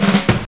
JAZZ FILL 3.wav